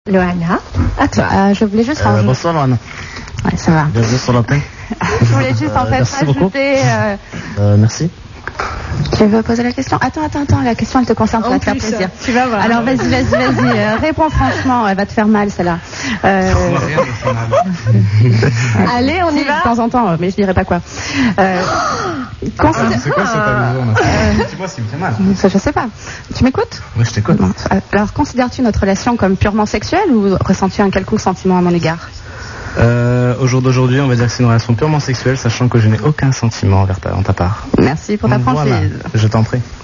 Jeu de la vérité : Jean-Edouard annonce qu'il n'a aucuns sentiments pour Loana